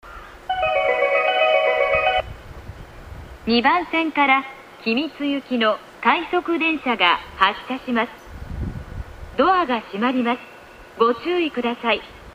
２番線内房線
発車メロディー途中切りです。